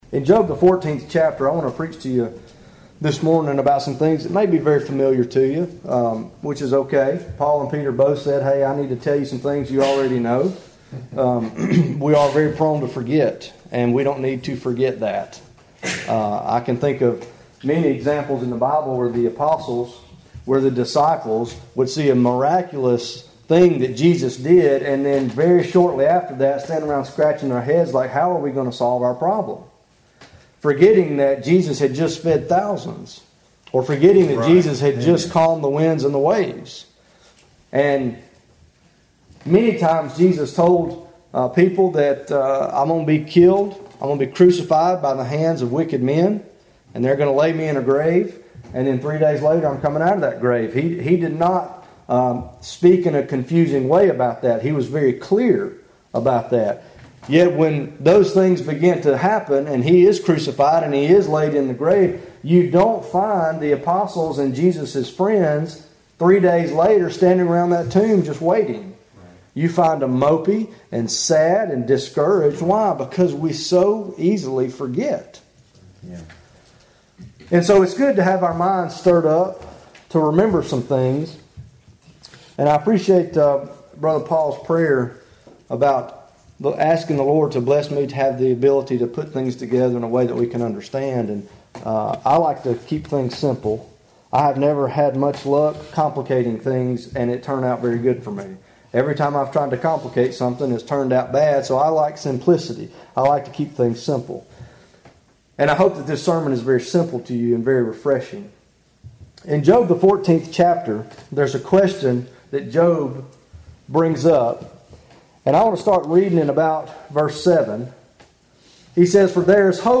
What gives us assurance of our salvation? What makes us feel as though we are saved? In this message preached at New Antioch